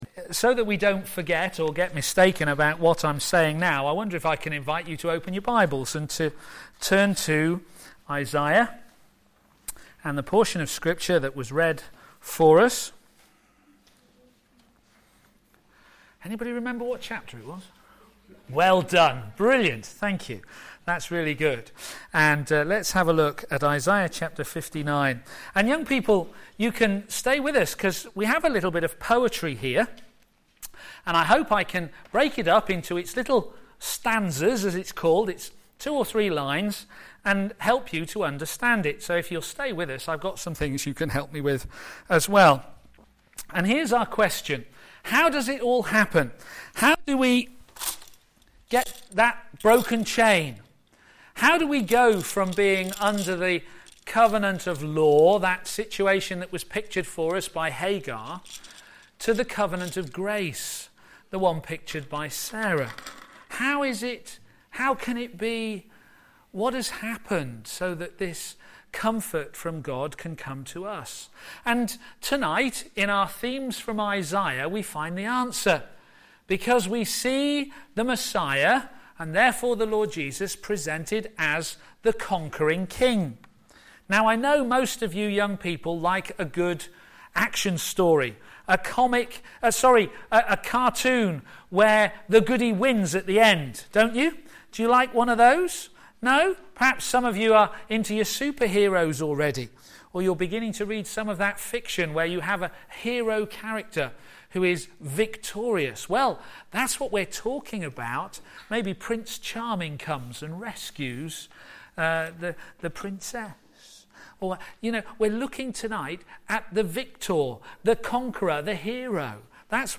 Theme: The Conquering King Sermon